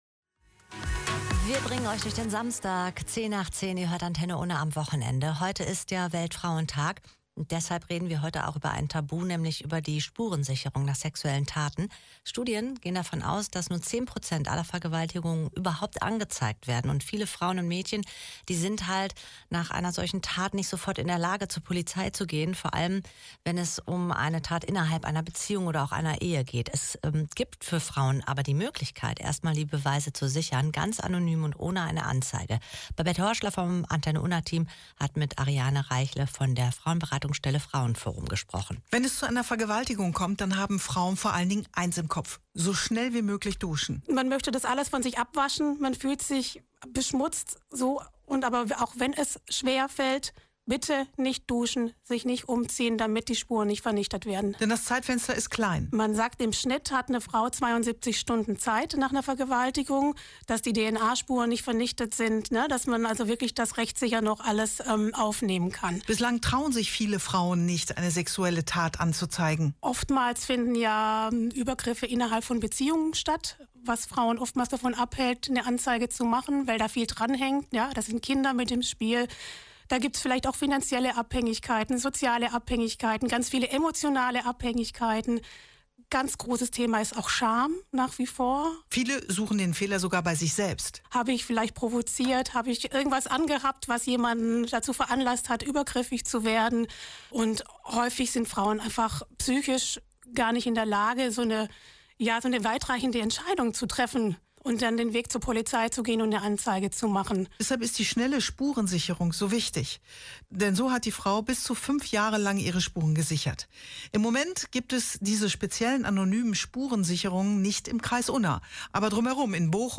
Anonyme Spurensicherung: Interview